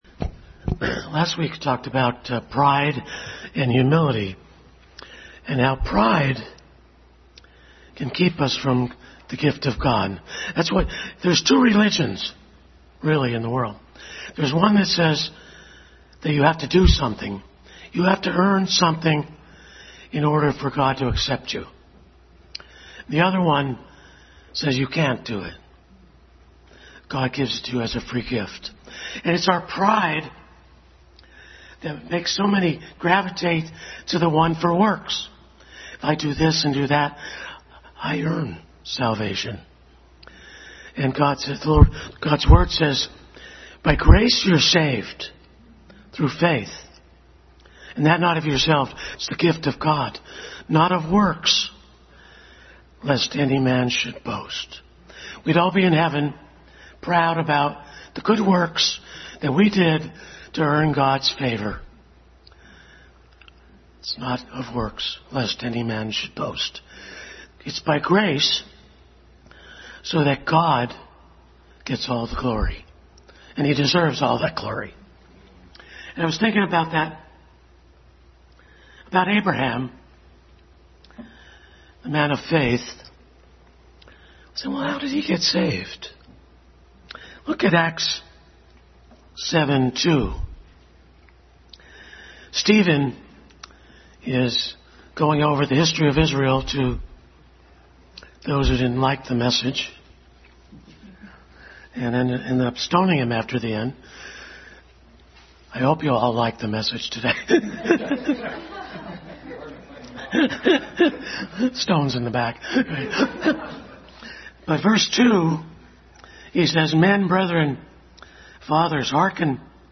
God of Glory Passage: Acts 7:2, Psalm 8:3-5, Psalm 23, Isaiah 6:1-5, Exodus 3:2-6, Joshua 5:13-15, Luke 5:4-9 Service Type: Family Bible Hour Family Bible Hour message.